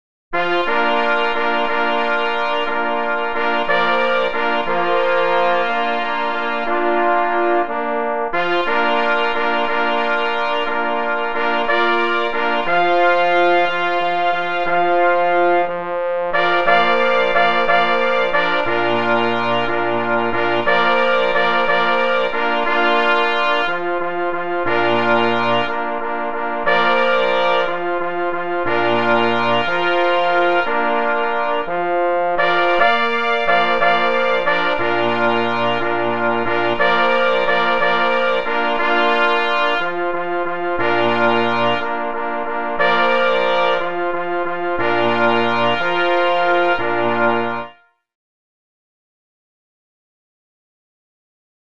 na 2 plesy i 2 parforsy
na 2 plessy i 2 par force